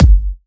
edm-kick-74.wav